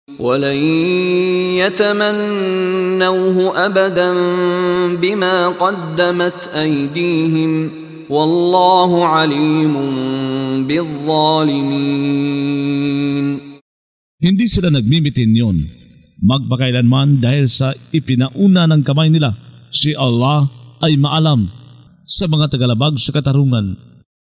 Pagbabasa ng audio sa Filipino (Tagalog) ng mga kahulugan ng Surah Al-Baqarah ( Ang Baka ) na hinati sa mga taludtod, na sinasabayan ng pagbigkas ng reciter na si Mishari bin Rashid Al-Afasy. Ang pag-uutos ng pagsasakatuparan ngkhilāfah (paghalili) saLupa sa pamamagitanng pagpapanatili ng Islām at pagsuko kayAllāh at ang pagbibigay-babala laban sa kalagayan ngmga anak ni Israel.